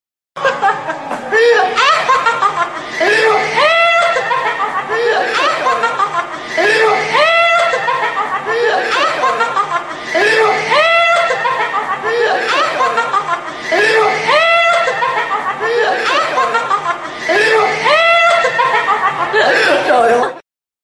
amthanhnen.mp3